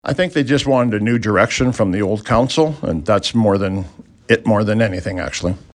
Rocky Mountain House mayor Shane Boniface speaking to the change town voters sought in the 2025 municipal election